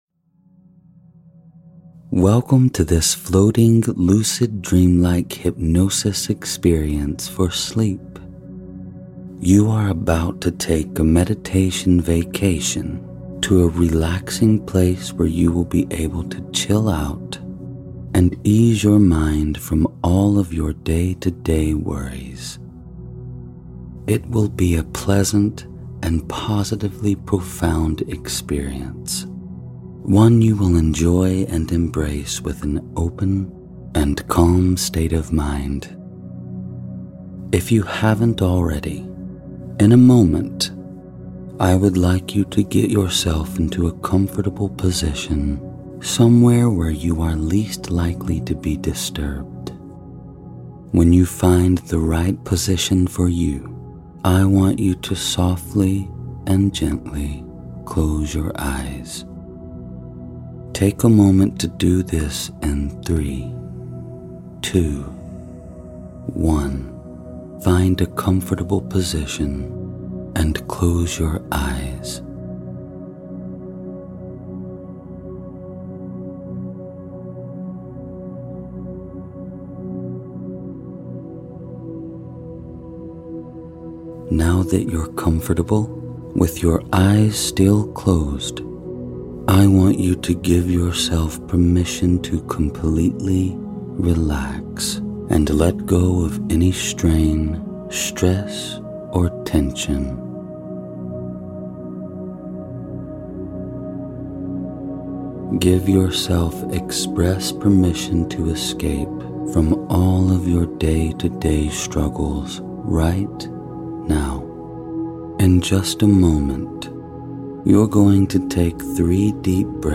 Use this sleep hypnosis and guided meditation session to give yourself that floating lucid dream like feeling that you find so relaxing and comforting.